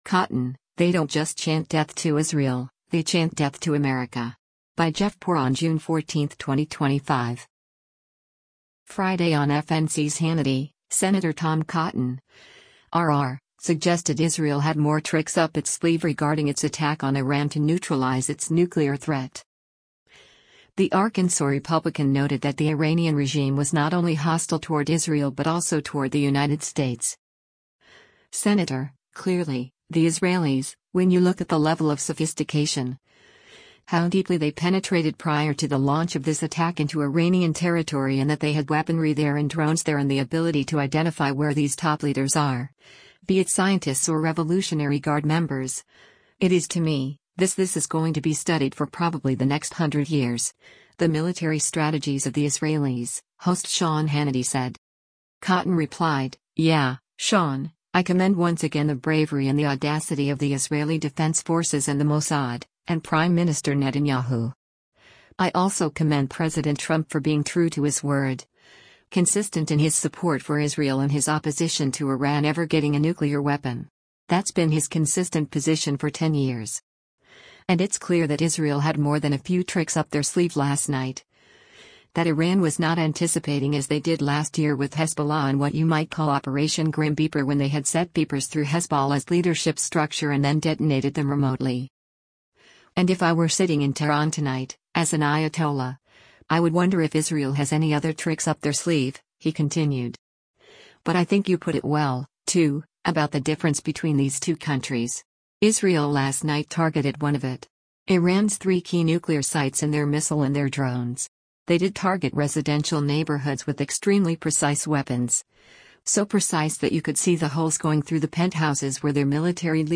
Friday on FNC’s “Hannity,” Sen. Tom Cotton (R-AR) suggested Israel had more “tricks” up its sleeve regarding its attack on Iran to neutralize its nuclear threat.